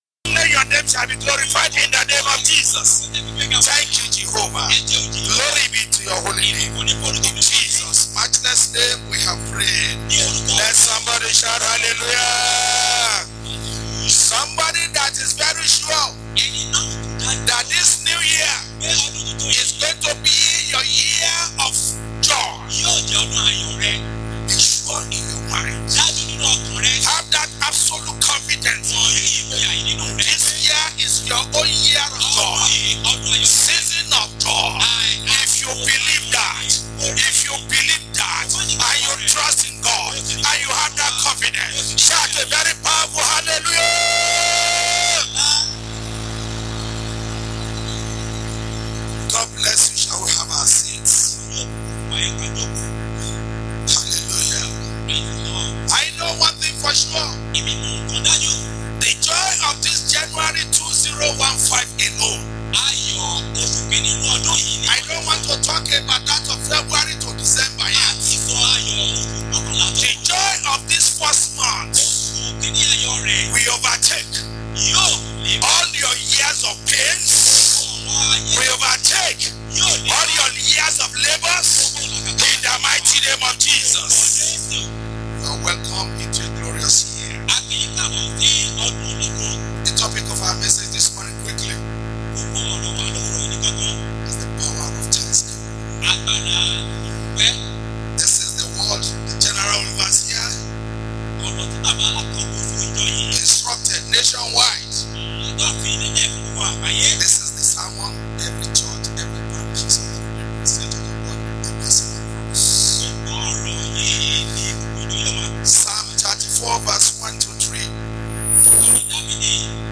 Posted in Thanksgiving Service